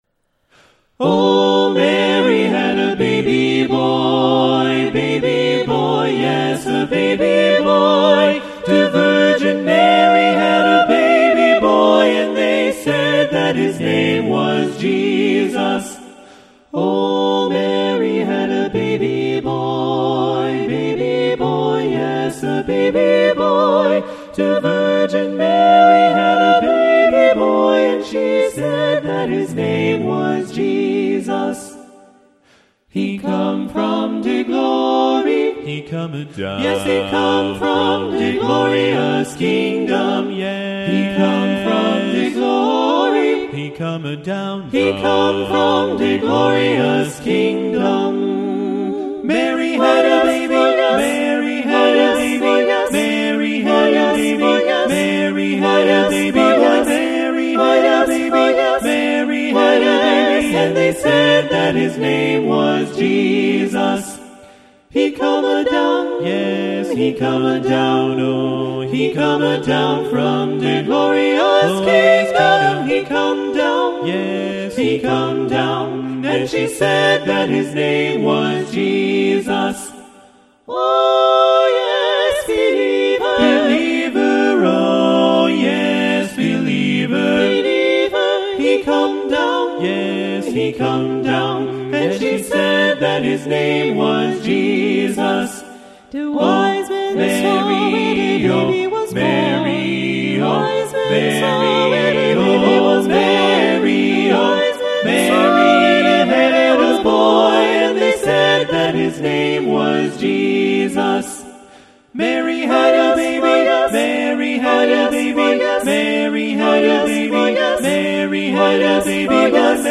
Composer: Spiritual
Voicing: SATB a cappella